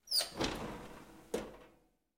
Звуки духовки
Звук сработавшего таймера духовки